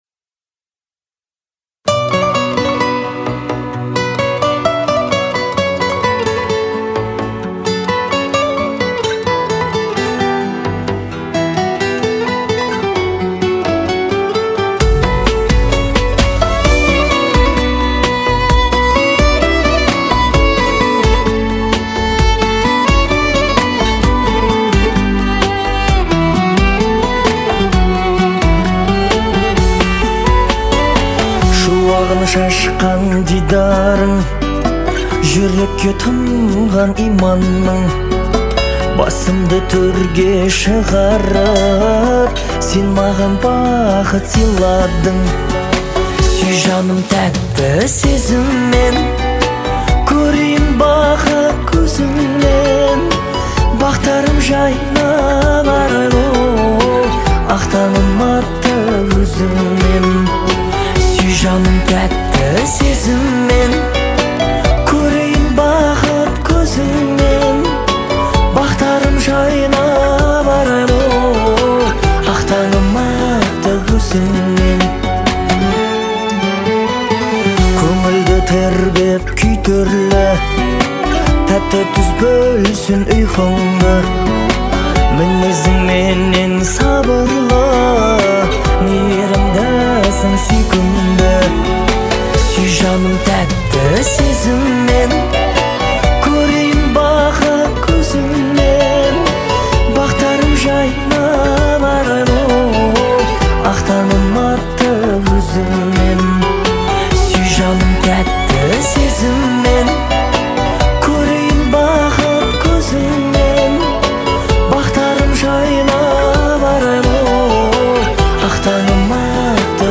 это яркий образец казахской поп-музыки
а мелодия легко запоминается.